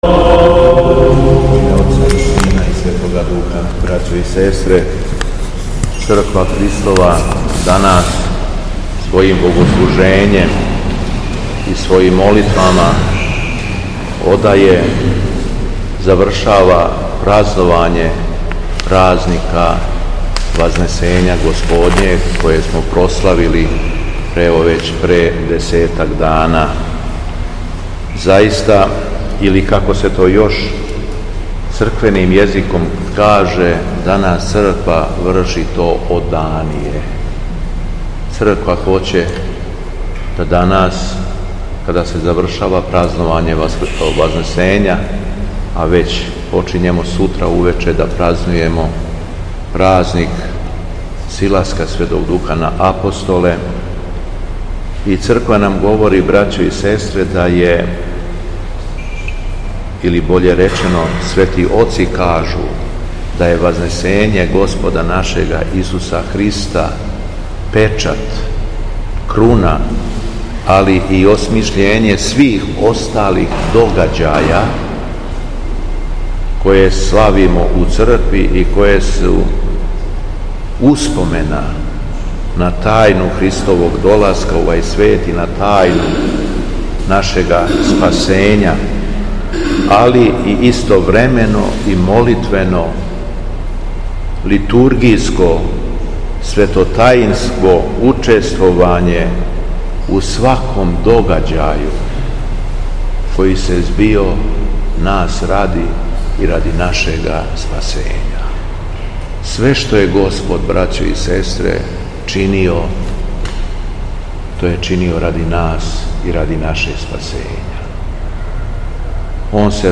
Беседа Његовог Високопреосвештенства Архиепископа крагујевачког и Митрополита шумадијског г. Јована
После прочитаног јеванђелског зачала, Високопреосвећени Архиепископ се обратио беседом сабраном народу: